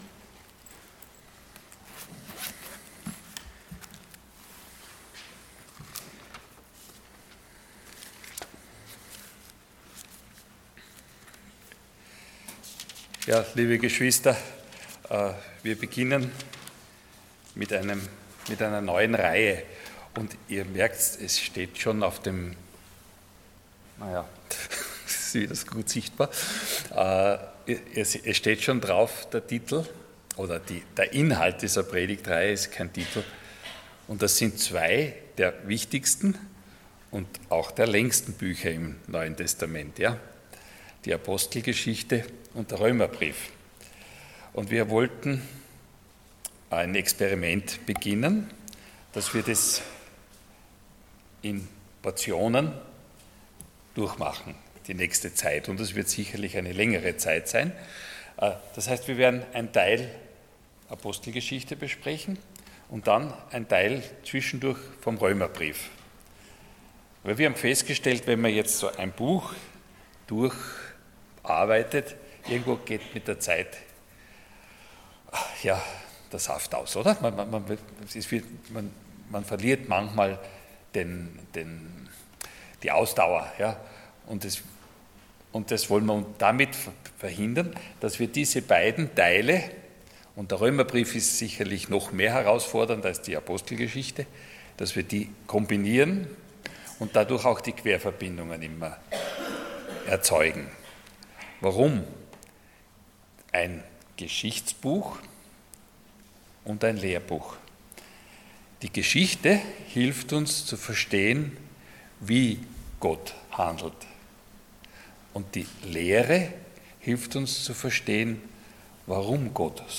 Passage: Acts 1:1-5 Dienstart: Sonntag Morgen